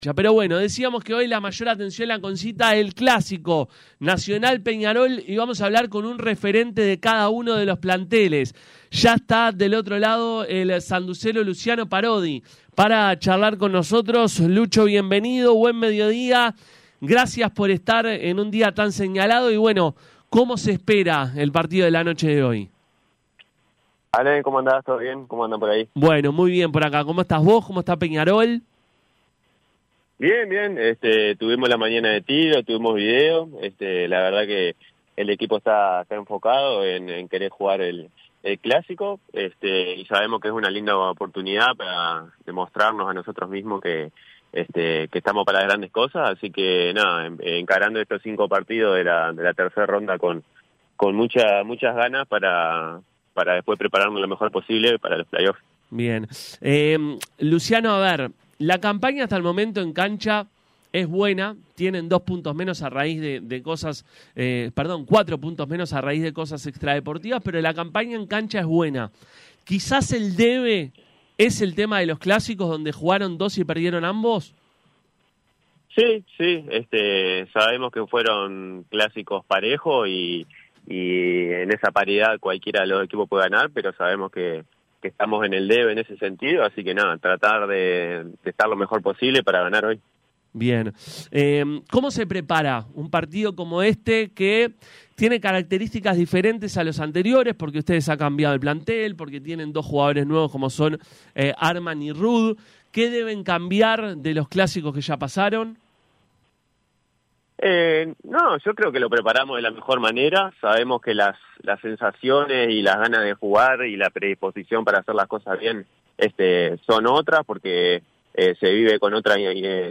Luciano Parodi jugador de Peñarol habló con Pica La Naranja previo al clásico de esta noche frente a Nacional.